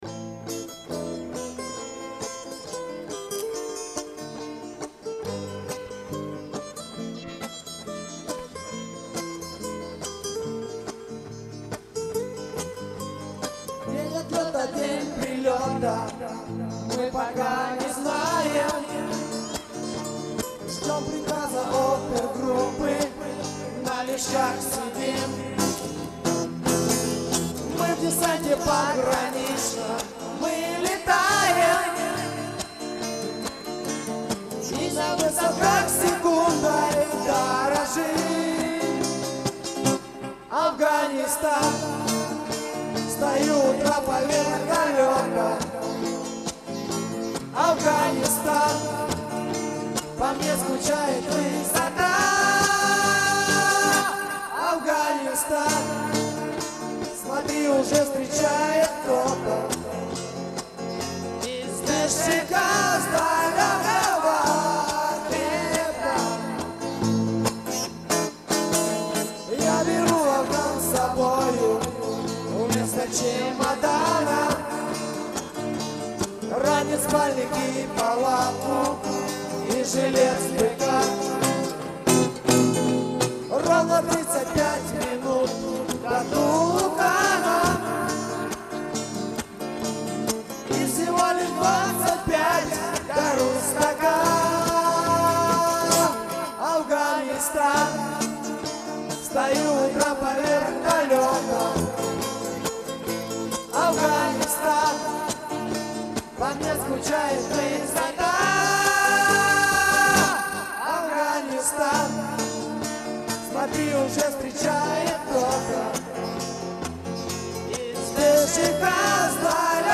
Записывали мы в клубе отряда (на той аппаратуре, что была).